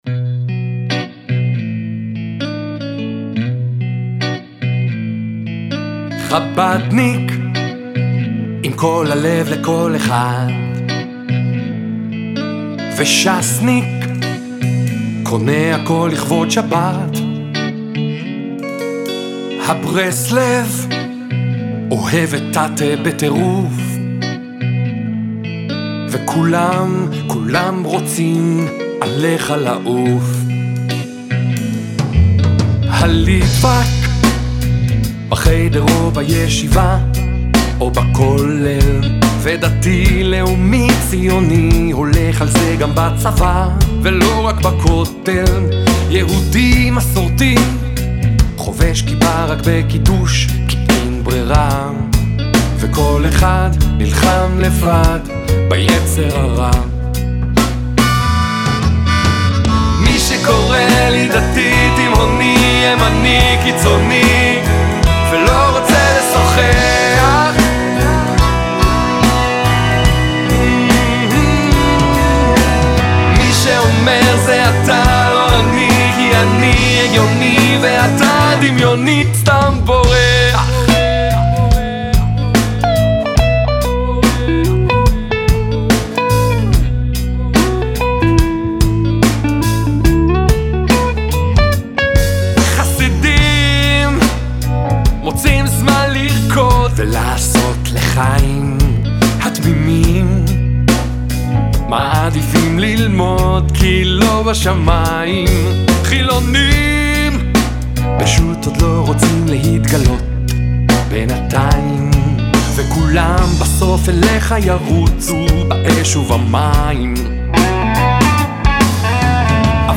קולו רועם, העיבוד עכשווי, והטקסט מעורר למחשבה.